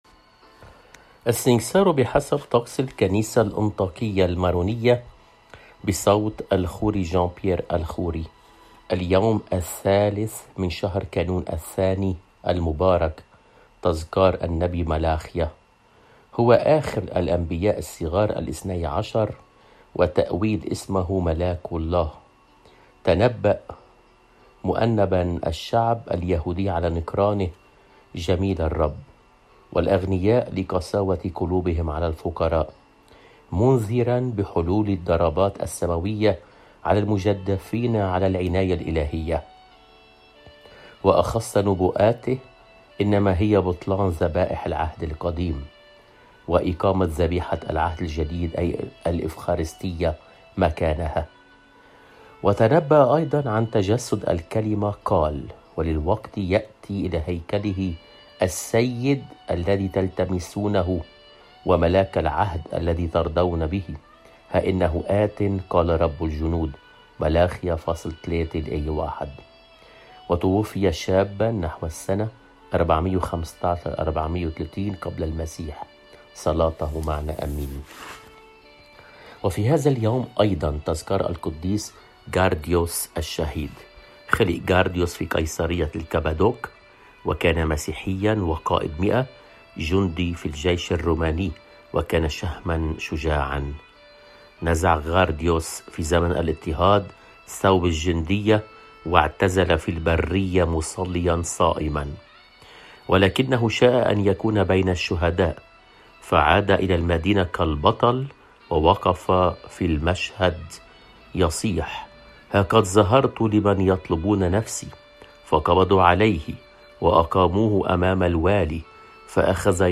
قدّيس يوم ٣ كانون الثانيMP3 • 1336KB